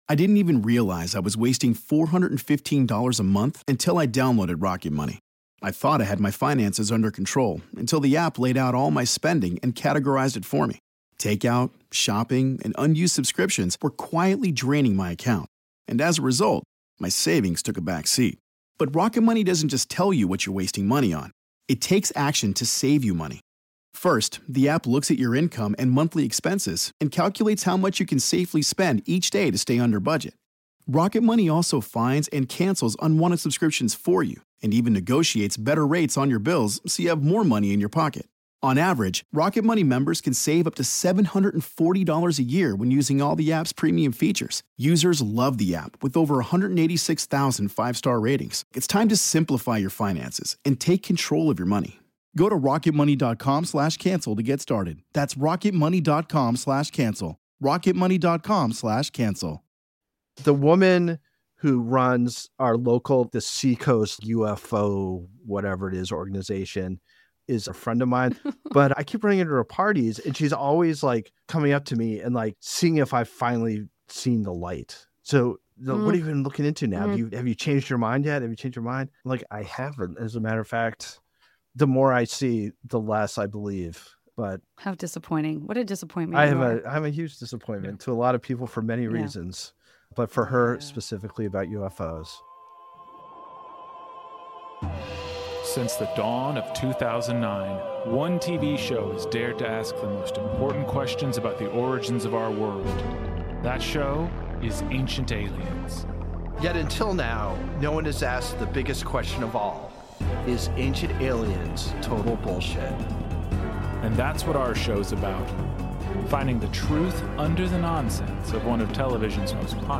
joined by actress and comedian Janet Varney